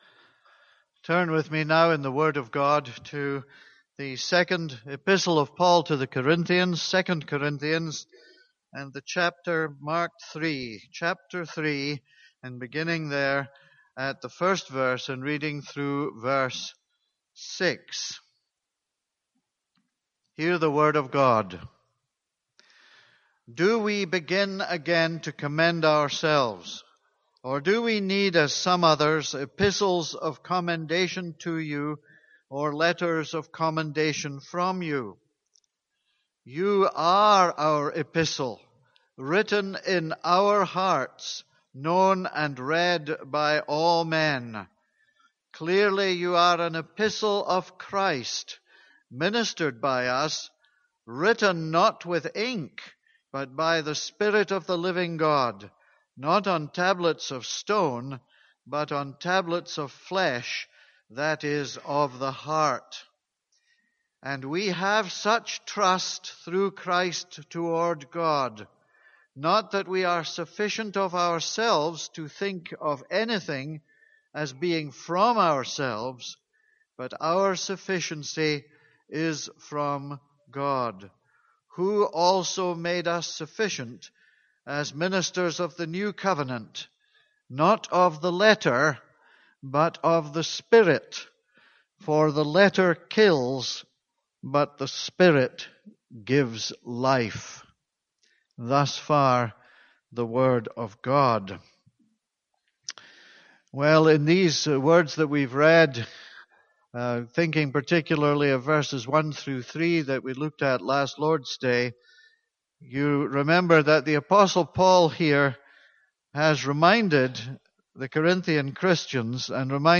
This is a sermon on 2 Corinthians 4:4-6.